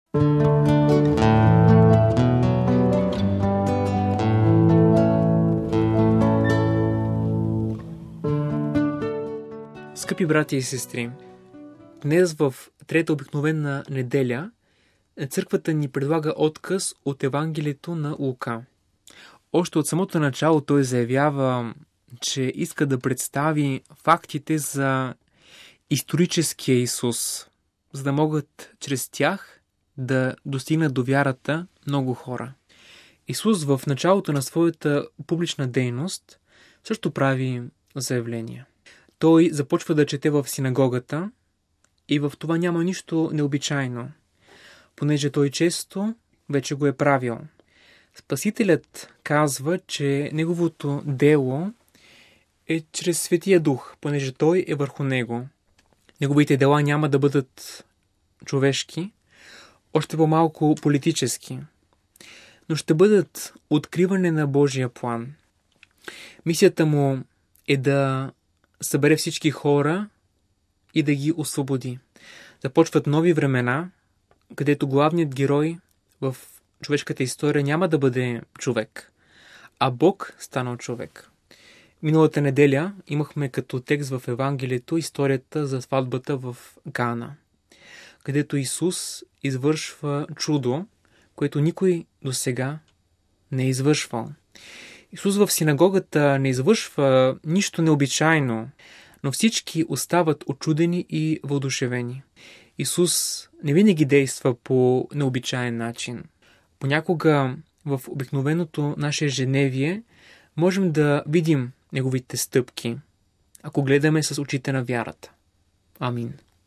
Неделна проповед